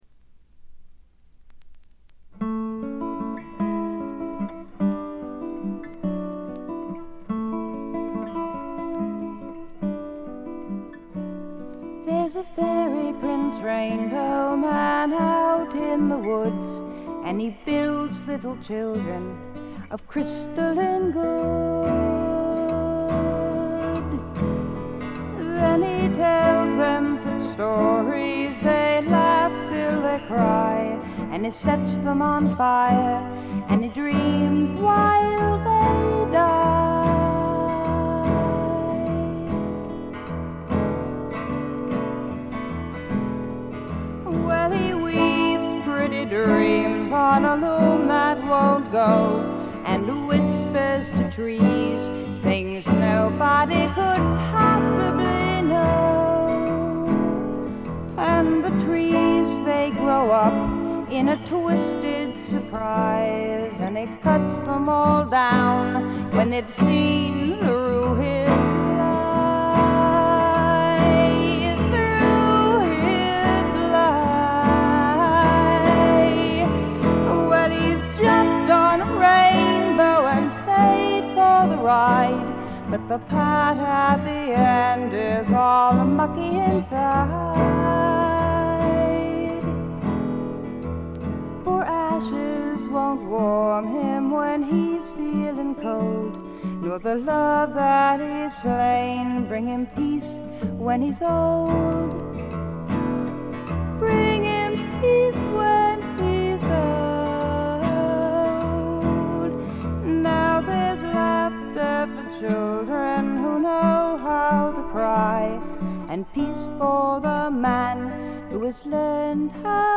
自身のギターによる弾き語りで、このアコースティックギターの音が何とも素晴らしく心のひだに沁みわたっていきます。
全体を貫く清澄な空気感と翳りのあるダークな感覚がたまりません。
試聴曲は現品からの取り込み音源です。
Vocals, Guitar